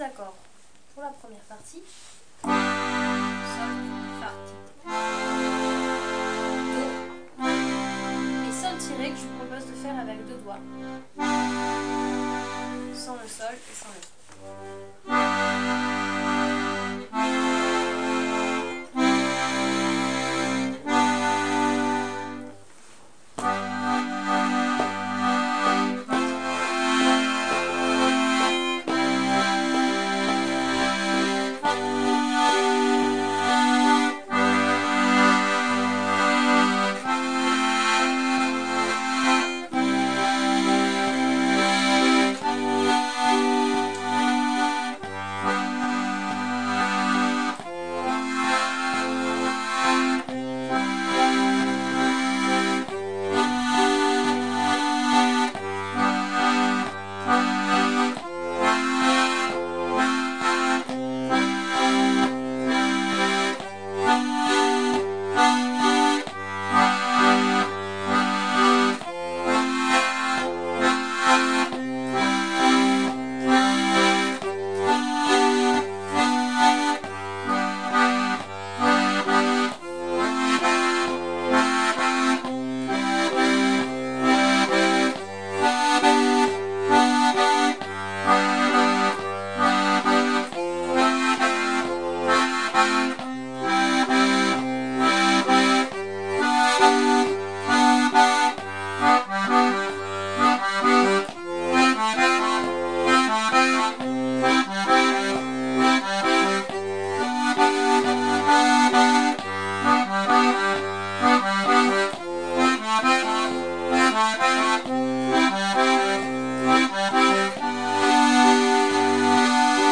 l'atelier d'accordéon diatonique
1)    Tes patits patins blancs (valse): la partition